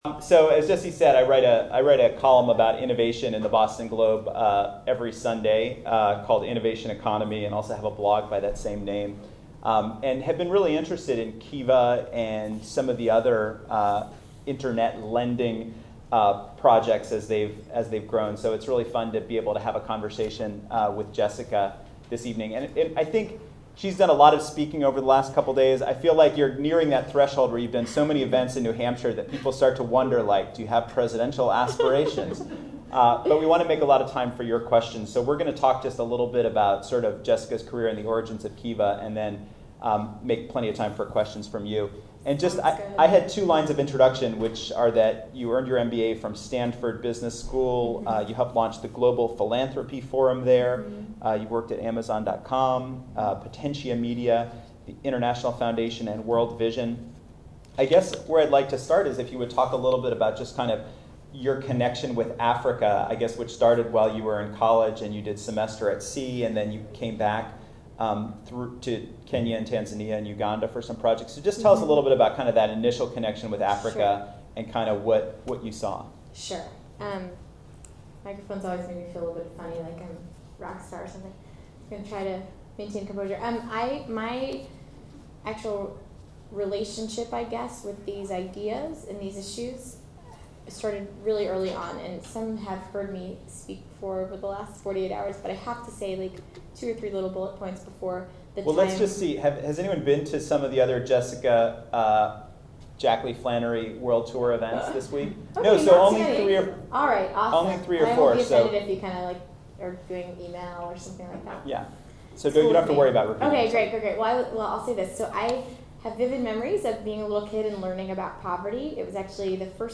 You can also download the audio of the entire conversation ( MP3 ), or just click play below (it runs about 1:45). 1. The challenge We don't do a good enough job communicating to the rest of the world the innovative stuff -- and important problem-solving -- that takes place here in New England.